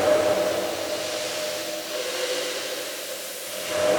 Index of /musicradar/sparse-soundscape-samples/Sample n Hold Verb Loops